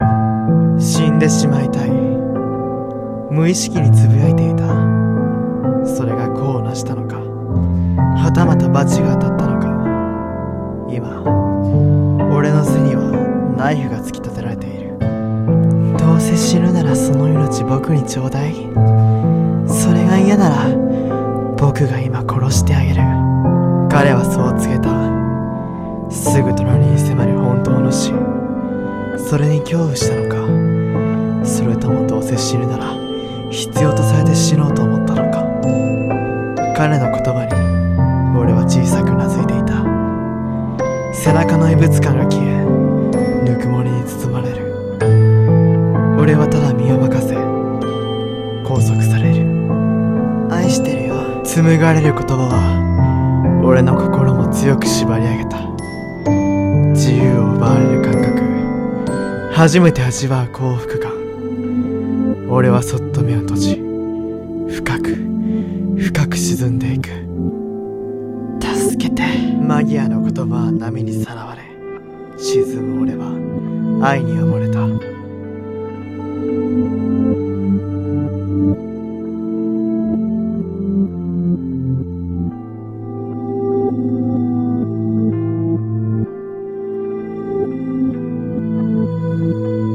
【声劇】 死にたい私は愛に溺れる